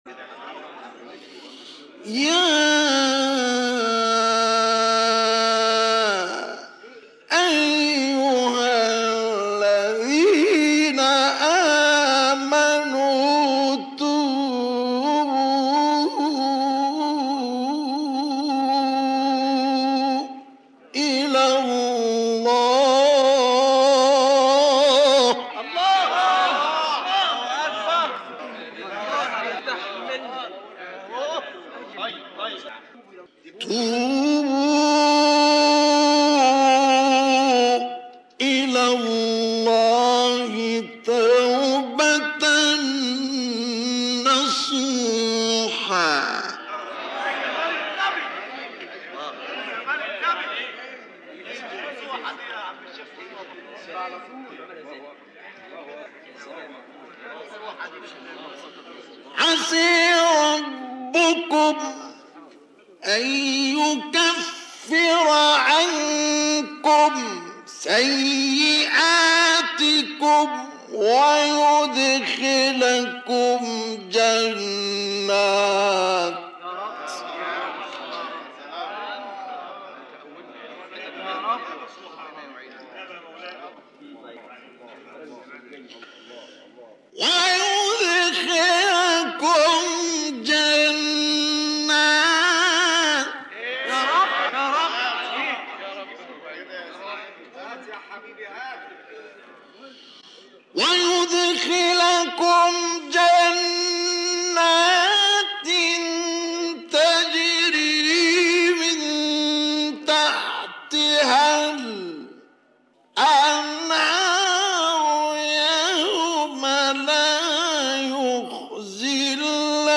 گروه شبکه اجتماعی: تلاوت‌های متفاوت آیه 8 سوره مبارکه تحریم با صوت مصطفی اسماعیل را می‌شنوید.
مقطعی از تلاوت در دهه هشتاد میلادی در اسکندریه